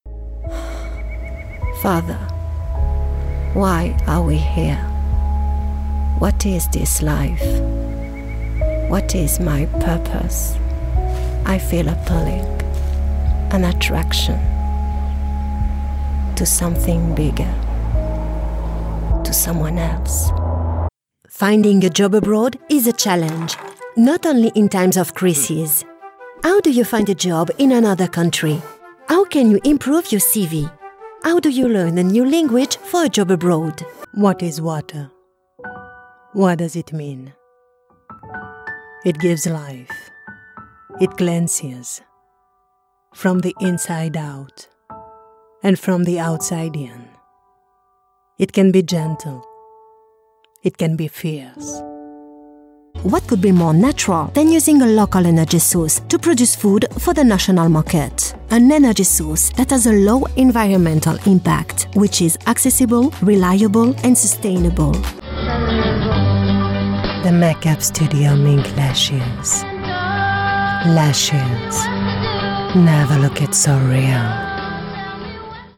Demonstração Comercial
Atriz de dublagem francesa nativa da França, sotaque neutro.
Minha voz pode ser natural, sensual, dramática, brincalhona, amigável, institucional, calorosa e muito mais